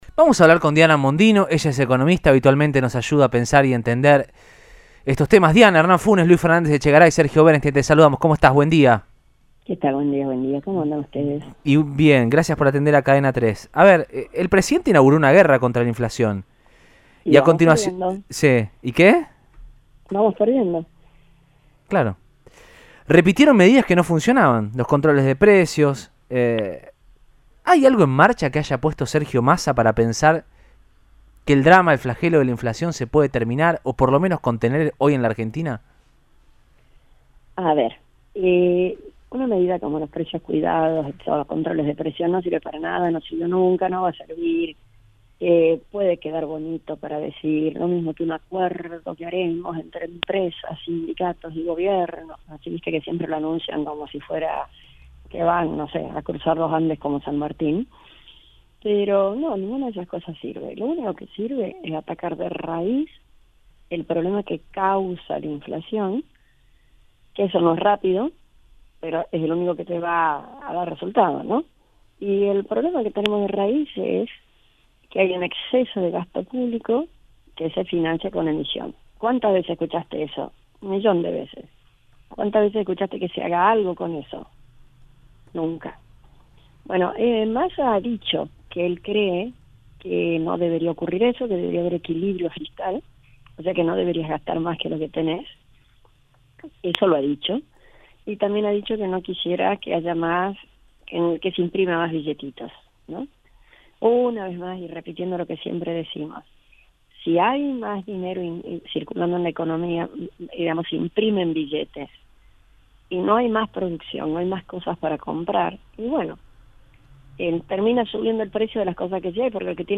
El índice de precios de julio fue récord en las últimas décadas y no para de subir. La economista Diana Mondino charló con Cadena 3 Rosario y brindó su mirada sobre las medidas que toma el gobierno para frenar la escalada.